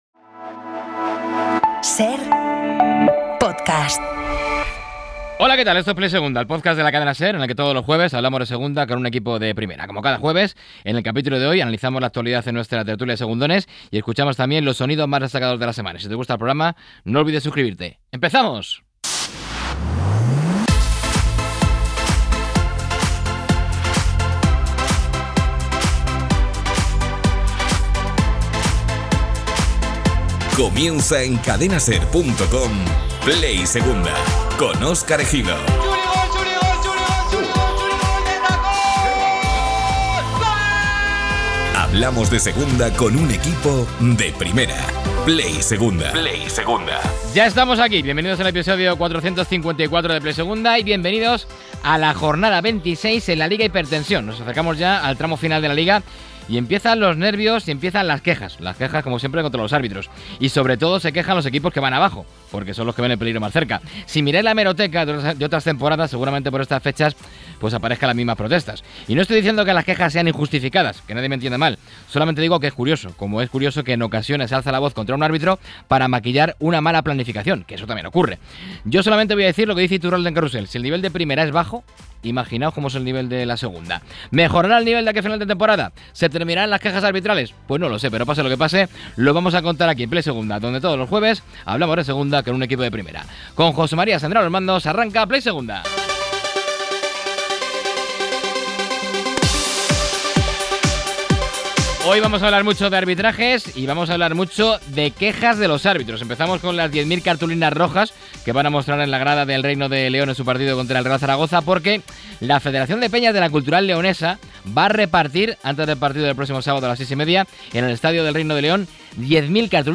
Las quejas arbitrales, las 10.000 cartulinas de la Cultu en protesta al gol anulado contra el Málaga. La tertulia